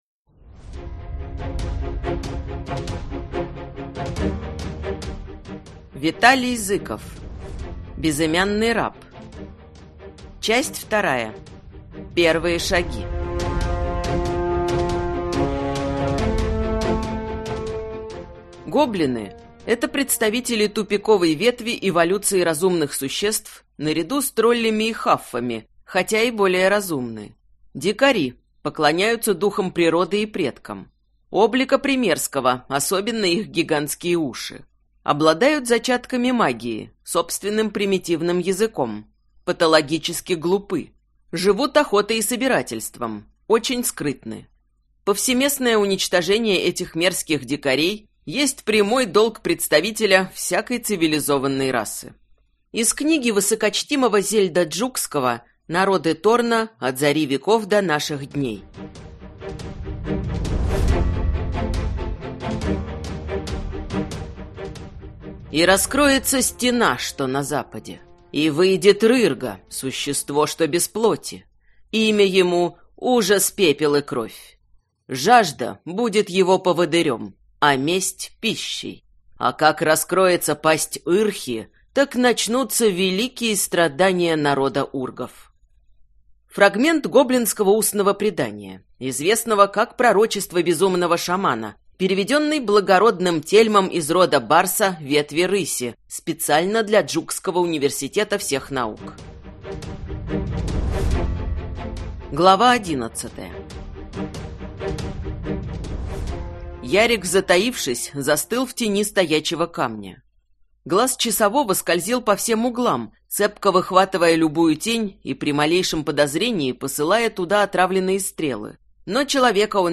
Аудиокнига Безымянный раб. Часть 2-я | Библиотека аудиокниг